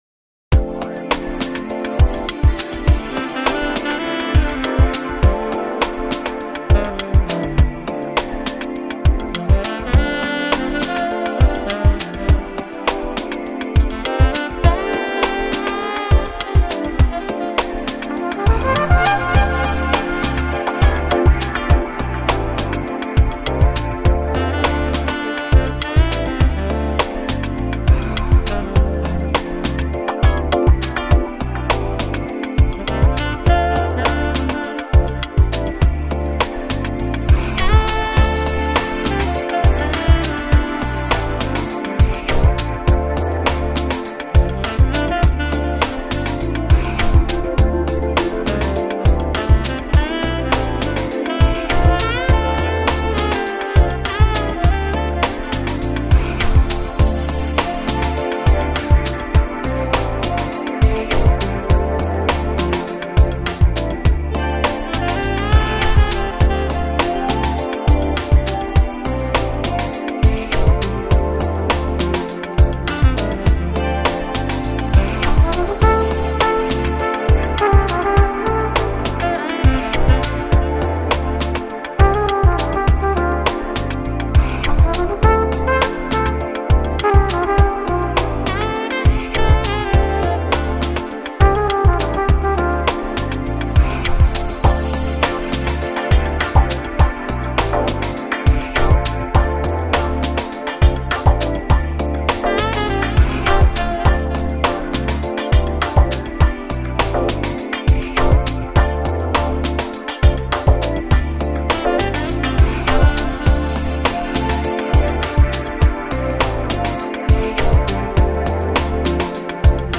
Lounge, Chill Out, Smooth Jazz, Easy Listening